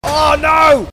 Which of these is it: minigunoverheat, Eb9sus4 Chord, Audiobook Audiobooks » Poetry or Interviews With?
minigunoverheat